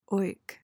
PRONUNCIATION: (oik) MEANING: noun: A person perceived as uncouth, unpleasant, and of lower social standing.